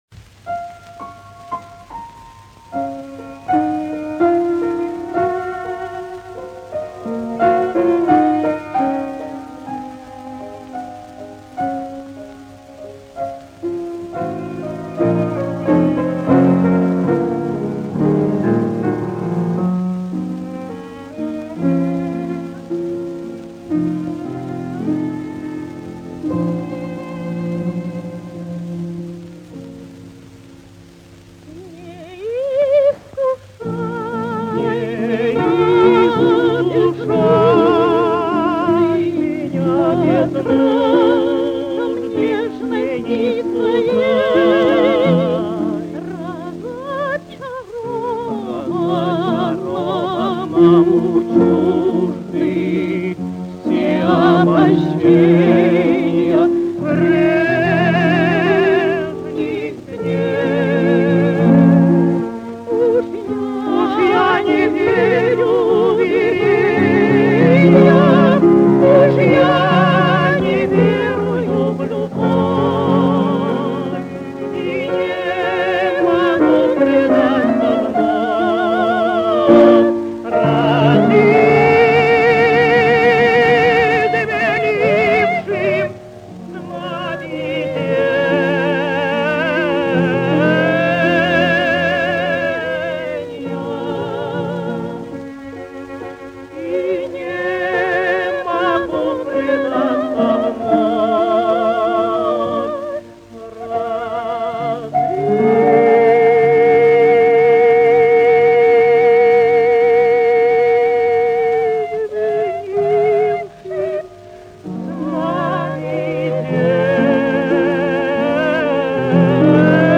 Тут гениально всё: стихи Боратынского, музыка Глинки, а исполнители — тоже гении: в первом случае дуэт Козловского и Неждановой, а во втором поёт внучка Поэта, Надежда Андреевна Обухова.